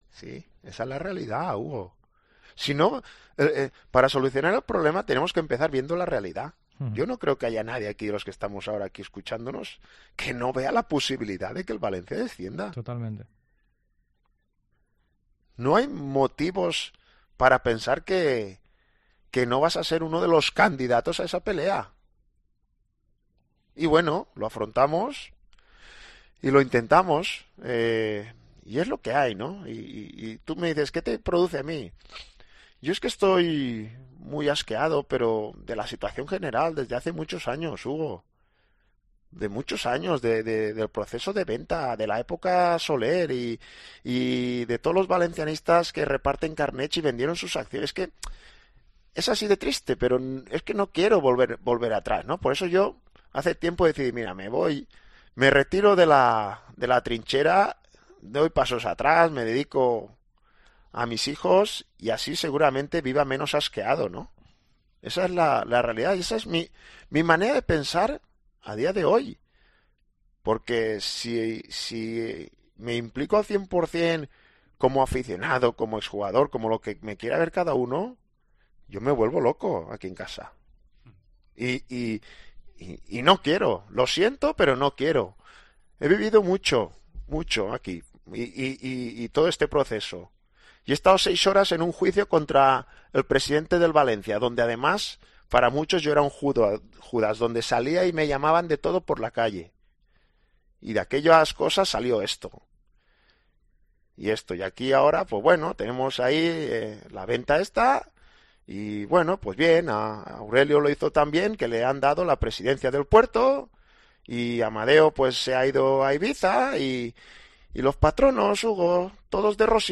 ENTREVISTA COPE
David Albelda ha pasado este mediodía por los micrófonos de Deportes COPE Valencia para analizar la situación del Valencia CF. El equipo dirigido por Rubén Baraja perdió ayer ante el Getafe en el Coliseum Alfonso Pérez y es penúltimo de LaLiga a falta de 16 partidos para terminar el campeonato.